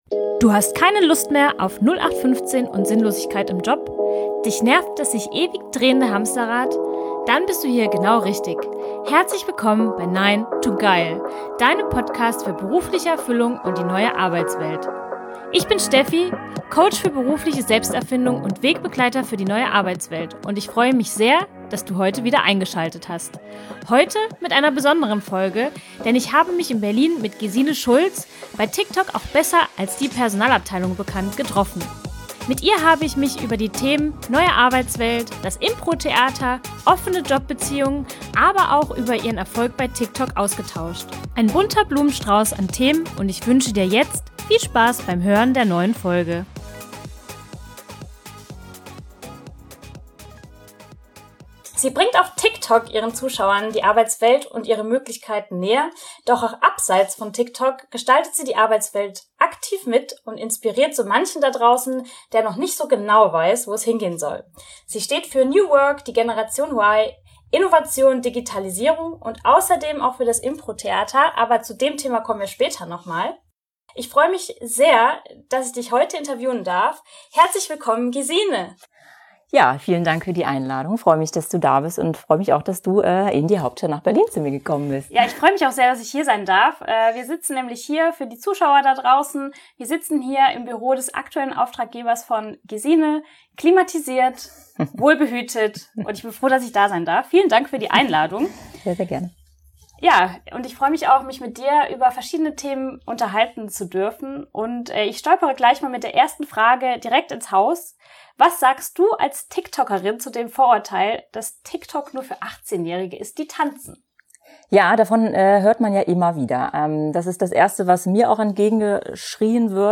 #002 - Interview